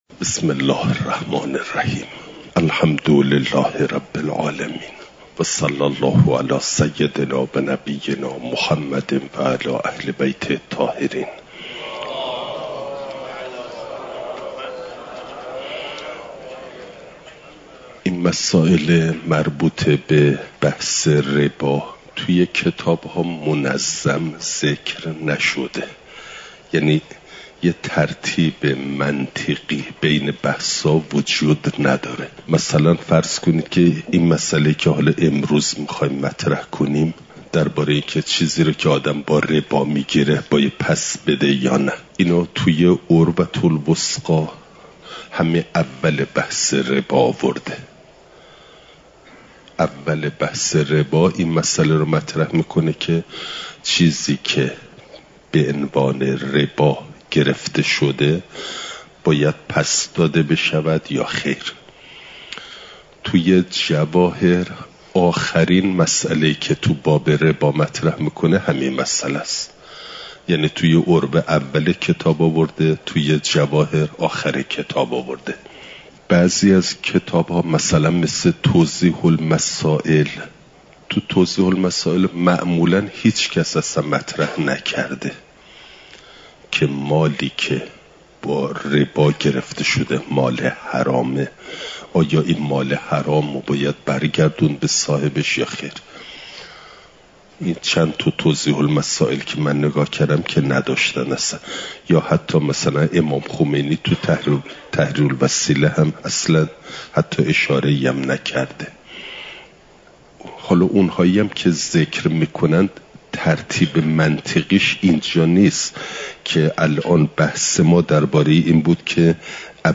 نظام اقتصادی اسلام؛ مبحث ربا (جلسه۴۸) – دروس استاد